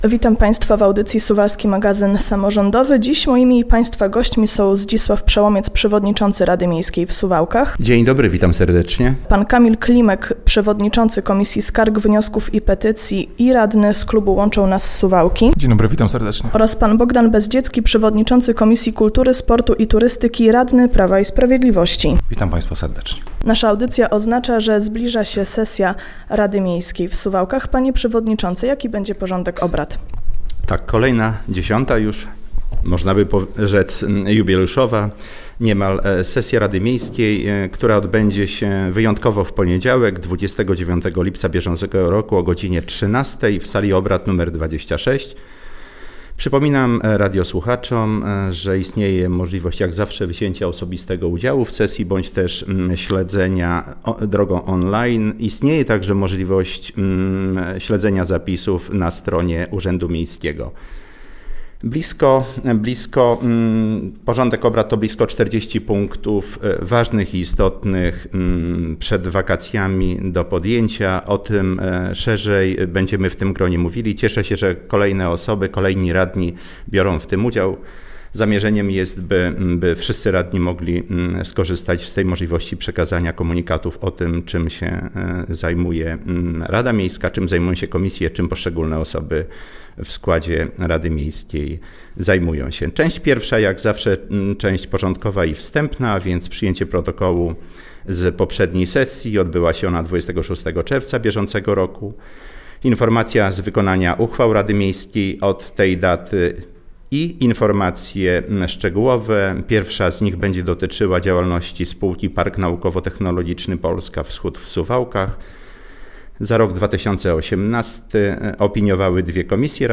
W piątek (26.07.19) w audycji udział wzięli Zdzisław Przełomiec, przewodniczący Rady Miejskiej, Bogdan Bezdziecki, przewodniczący Komisji Kultury, Sportu i Turystyki, przedstawiciel klubu Prawo i Sprawiedliwość i Kamil Klimek z klubu „Łączą Nas Suwałki” oraz przewodniczący Komisji Skarg, Wniosków i Petycji. Podczas audycji omówiony został temat sesji Rady Miasta, która odbędzie się w najbliższy poniedziałek (29.07.19) o godzinie 13.00 w Urzędzie Miejskim w Suwałkach. Radni pochylą się między innymi nad kwestią stypendiów sportowych, rozwoju sportu w mieście oraz rozpatrzone zostaną petycje i skargi.